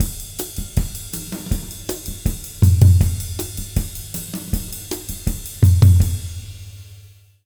Index of /90_sSampleCDs/USB Soundscan vol.08 - Jazz Latin Drumloops [AKAI] 1CD/Partition D/01-160E SIDE